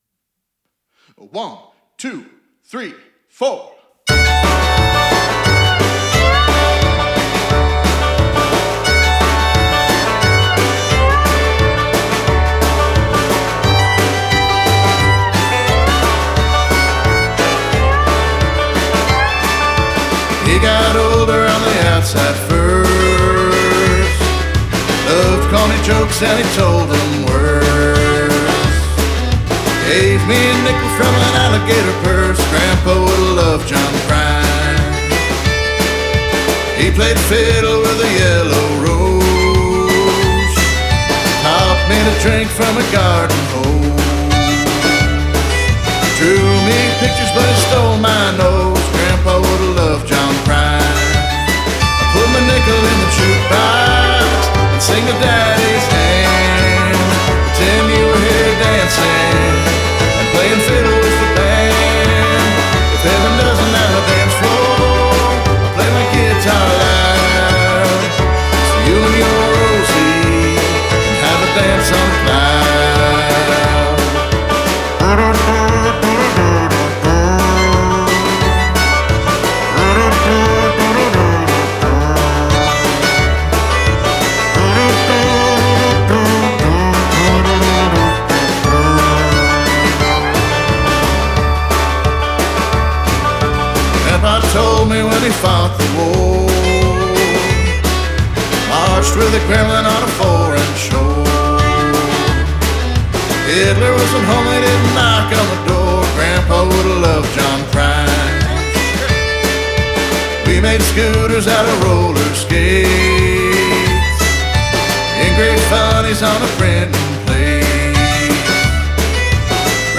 Vocals, guitar, bass
Drums
Fiddle arrangement